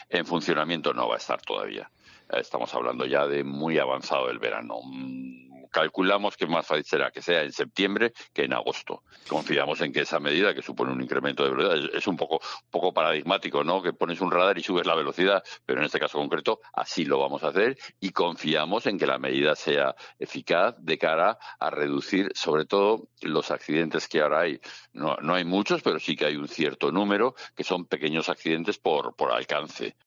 José Miguel Tolosa, delegado de tráfico en Cantabria
El delegado de tráfico en Cantabria, José Miguel Tolosa, ha confirmado en Herrera en COPE que el radar de tramo que la DGT va a instalar en Saltacaballo no estará operativo hasta después del verano.